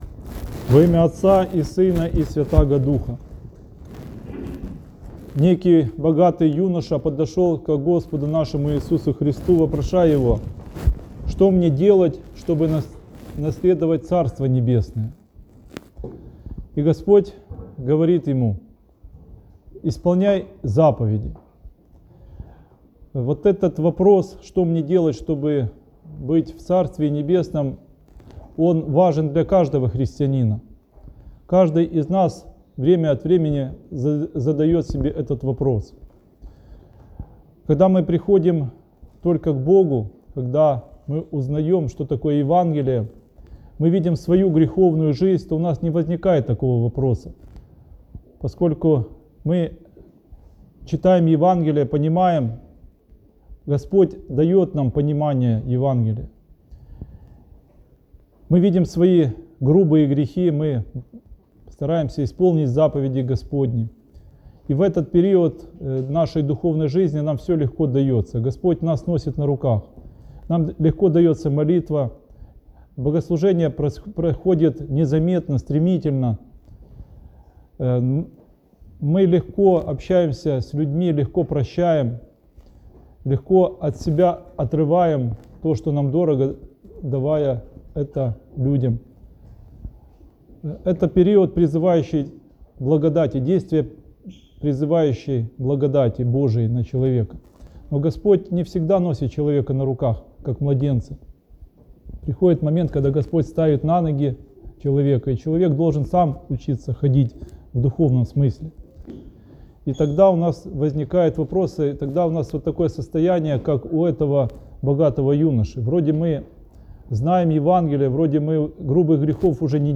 Проповедь. Беседа с богатым юношей (+АУДИО)